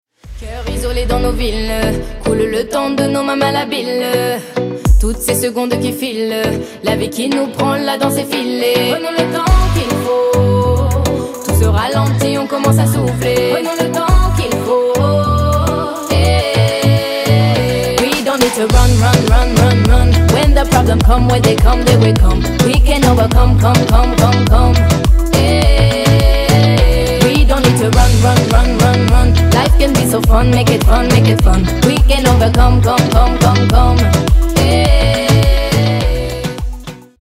• Качество: 224, Stereo
поп
женский вокал
dance
vocal